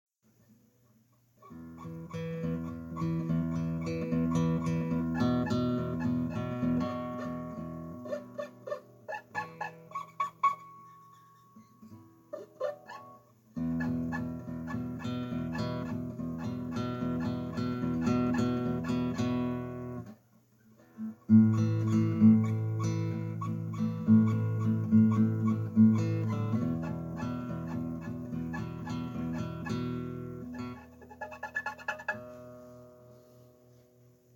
Metallischer Nachklang auf E-A-D-Saiten bei klassischer Gitarre
Ich habe neue Saiten (Albert Augustine Medium) auf meine klassische Gitarre aufgezogen, aber die 3 (ummantelten) Saiten E A D haben ein metallisches Quietschen, wie es mir nach früheren Saitenwechseln nie aufgefallen ist. So als würde ich mit trockener Haut auf bzw. entlang der Saiten hin und herreiben.